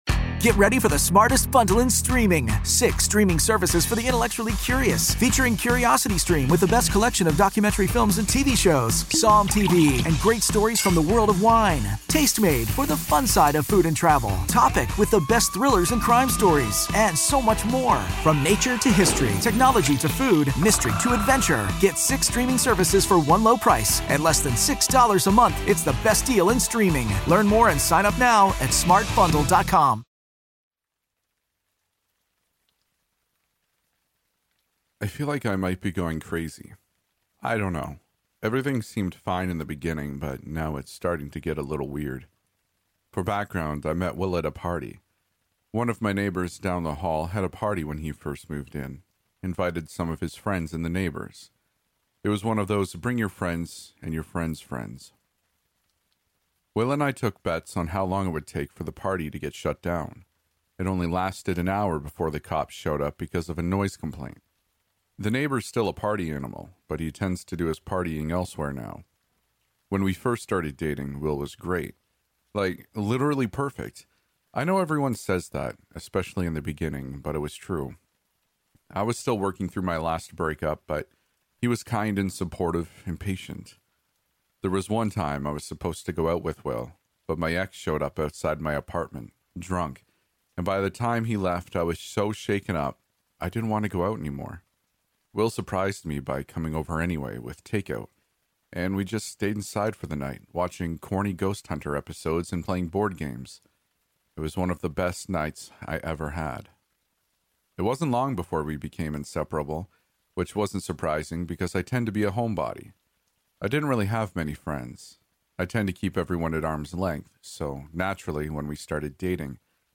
Episode 26 |My boyfriend won't let me see my friends anymore | Storytelling